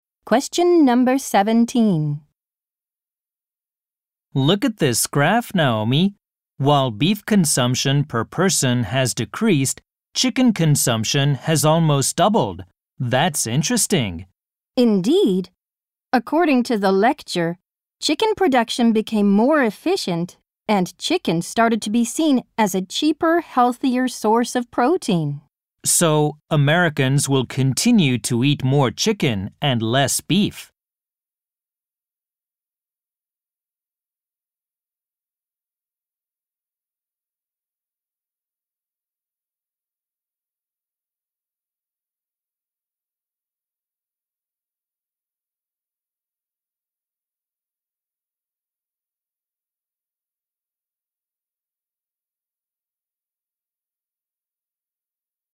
○共通テストの出題音声の大半を占める米英の話者の発話に慣れることを第一と考え，音声はアメリカ（北米）英語とイギリス英語で収録。
（新）第5問形式：【第14回】第3問　問16 （アメリカ（北米）英語+イギリス英語）